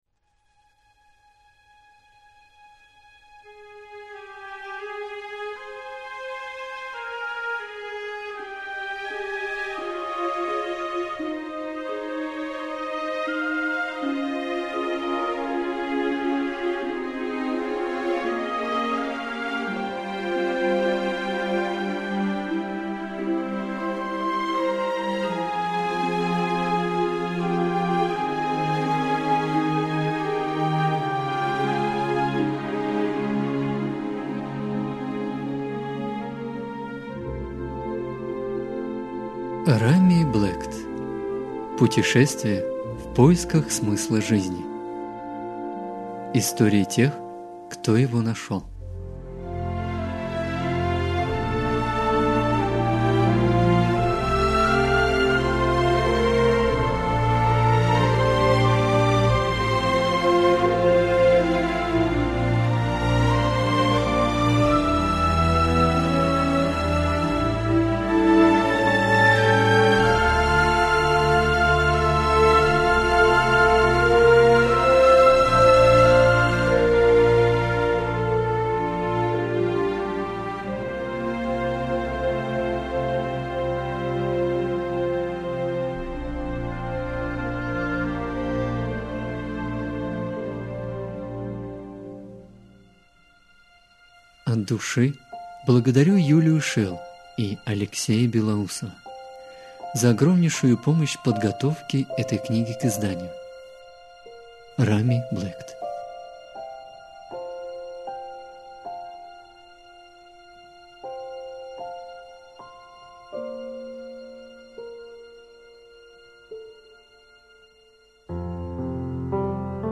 Аудиокнига Путешествия в поисках смысла жизни и своего предназначения | Библиотека аудиокниг